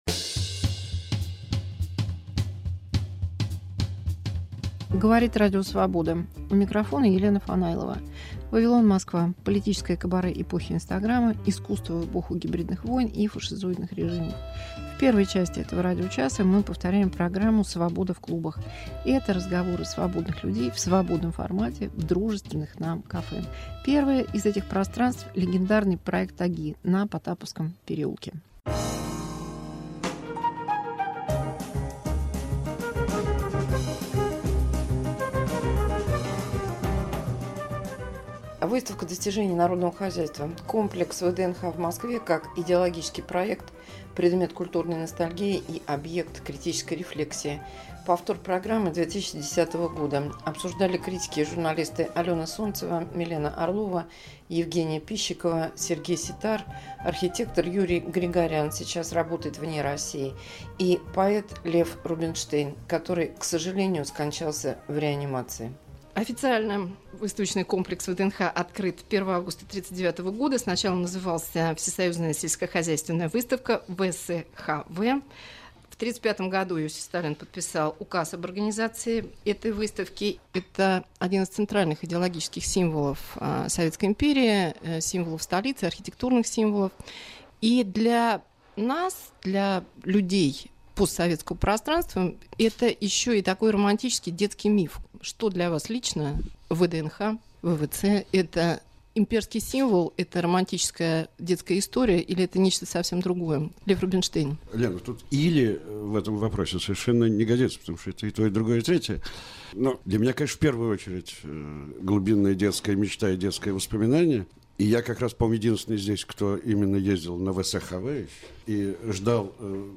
Архивная передача с участием Льва Рубинштейна